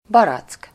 Ääntäminen
France (Paris): IPA: [a.bʁi.ko]